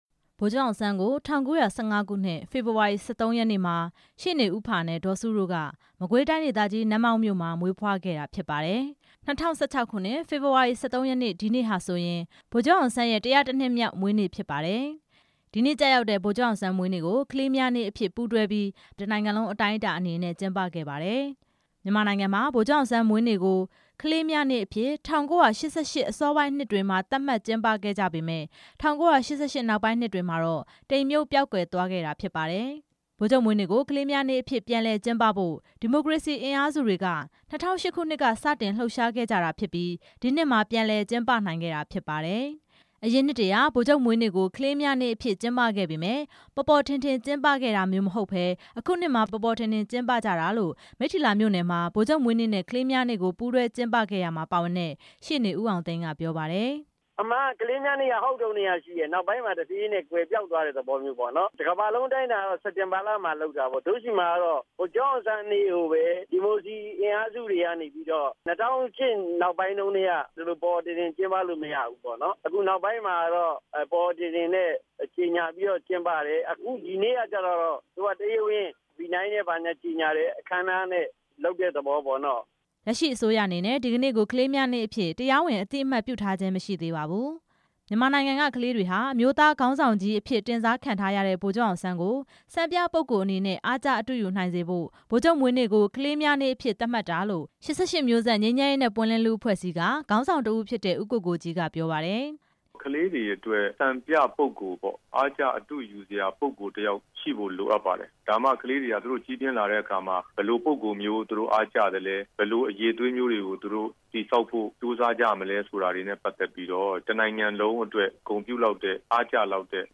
လူငယ်တွေကို ဗိုလ်ချုပ်အောင်ဆန်းလို ဖြစ်စေချင်တယ်လို့ အမျိုးသားဒီမိုကရေစီအဖွဲ့ချုပ် ဥက္ကဌ ဒေါ်အောင်ဆန်းစုကြည်က ဒီကနေ့ နေပြည်တော်က နှင်းဆီစံအိမ်ရှေ့မှာ ပြောလိုက်ပါတယ်။
ဗိုလ်ချုပ်အောင်ဆန်းရဲ့ ၁ဝ၁ နှစ်မြောက် မွေးနေ့အထိမ်းအမှတ်အဖြစ် ဒေါ်အောင်ဆန်းစုကြည်ရဲ့ နေပြည်တော် က နေအိမ်မှာ ဆွမ်းကပ်အလှူပွဲအပြီး သတင်းထောက်တချို့ကို ဒေါ်အောင်ဆန်းစုကြည်က ပြောလိုက်တာ ဖြစ် ပါတယ်။